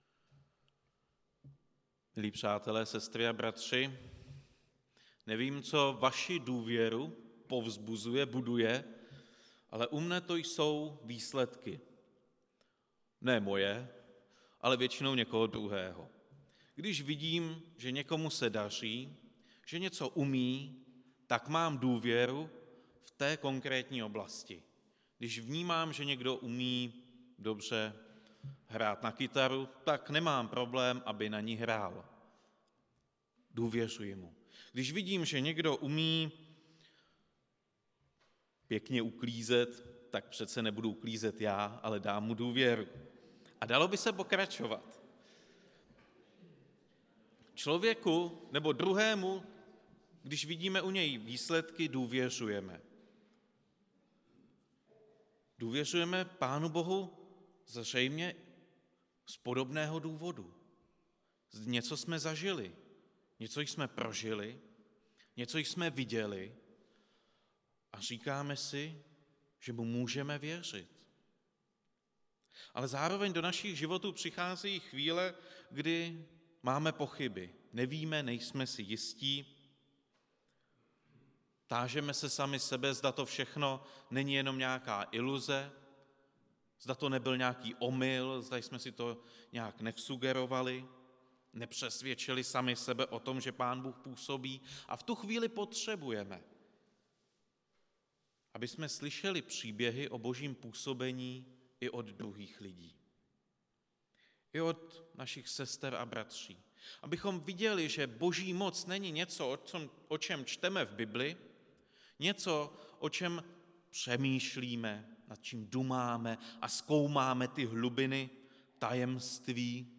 Kázání
Událost: Kázání